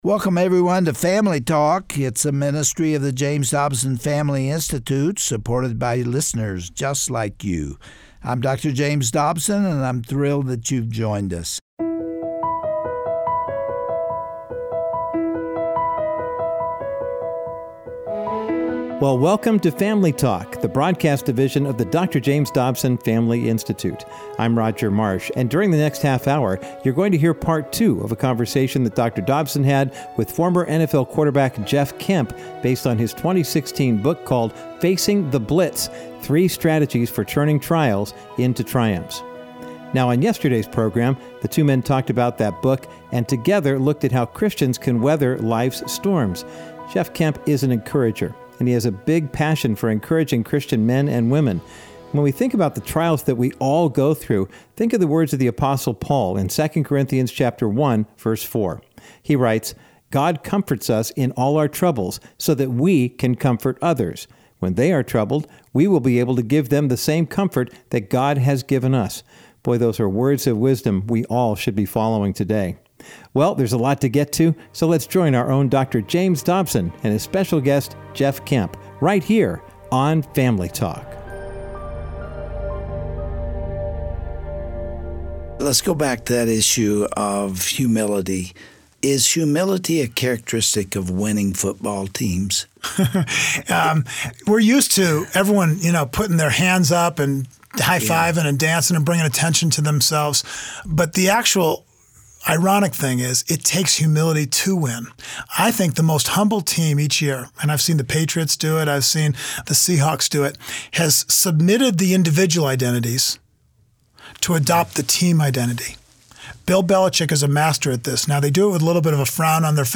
On today’s edition of Family Talk, Dr. James Dobson concludes his inspirational discussion with former NFL quarterback Jeff Kemp about his book, Facing the Blitz. Jeff describes humility as the key to success, whether it be on the football field, or in his relationships with family and friends.